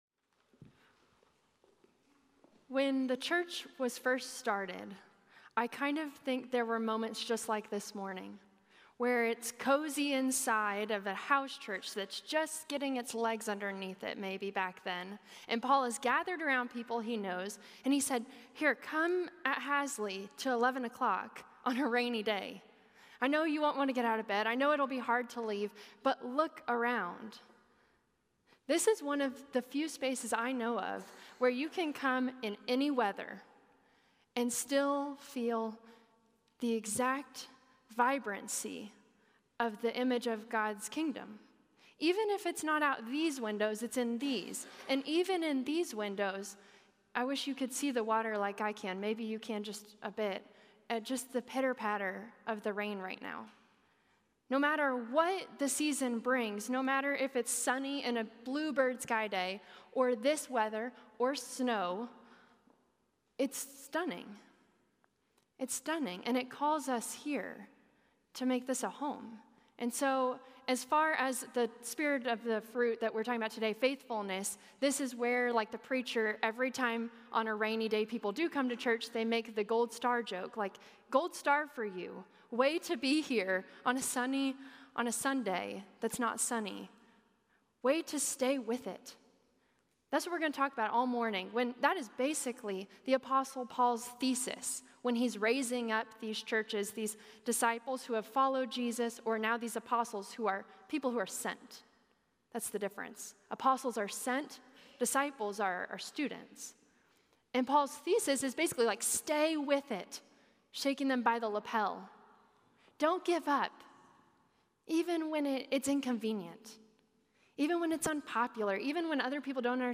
Message
A message from the series "Fruit of the Spirit."